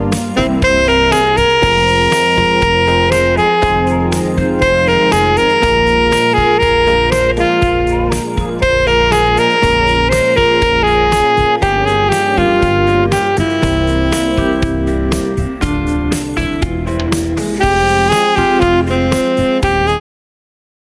230 Простых мелодий для саксофониста